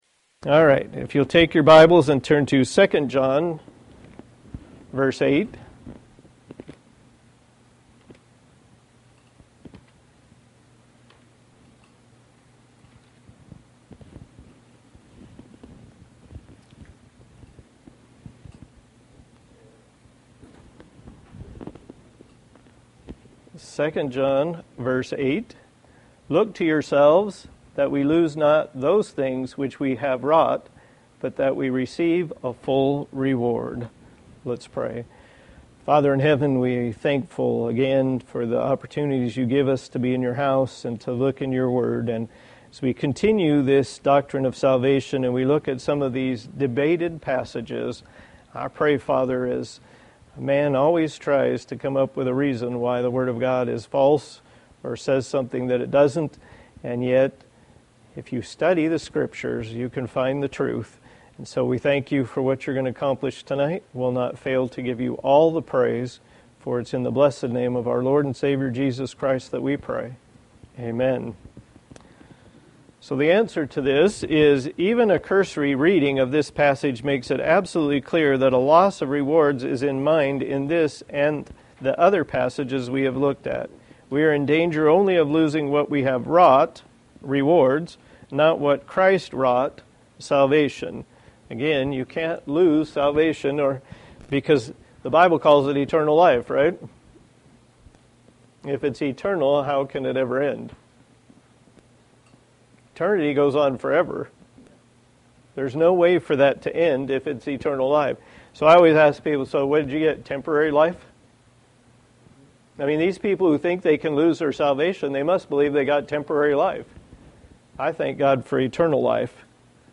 Service Type: Thursday Evening